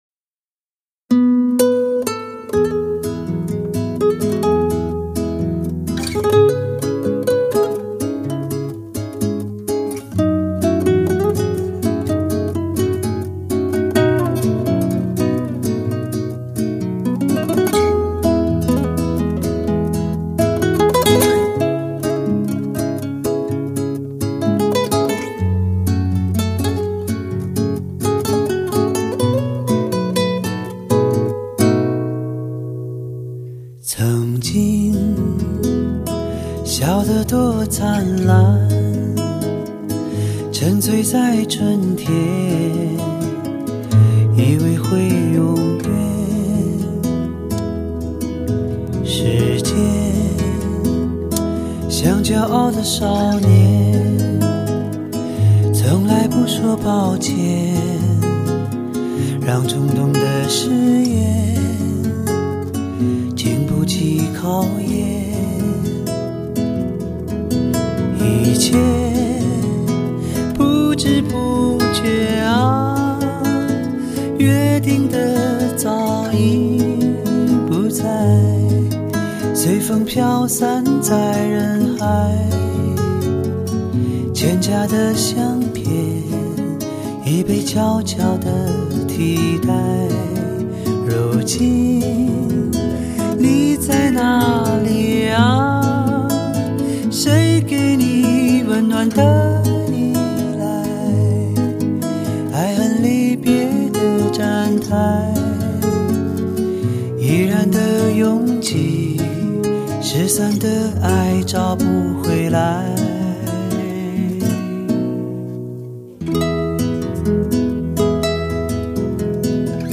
音乐风格: 民谣
第一次听他的歌，声音纯净、亲切，很喜欢。